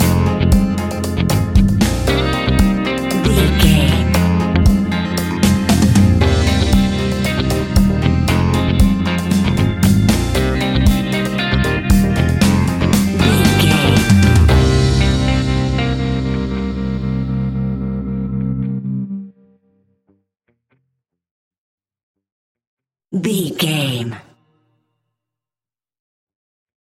Aeolian/Minor
laid back
chilled
off beat
drums
skank guitar
hammond organ
percussion
horns